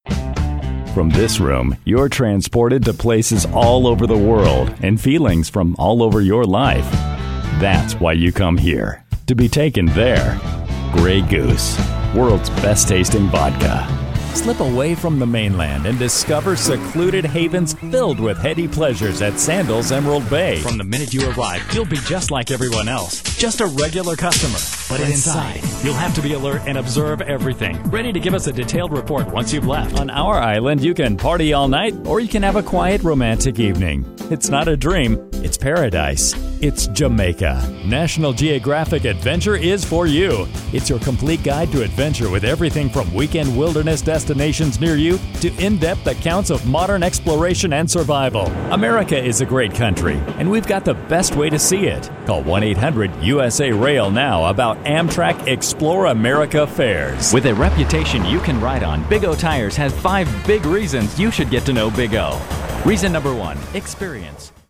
Never any Artificial Voices used, unlike other sites.
Adult (30-50) | Older Sound (50+)
0531Commercial_VO.mp3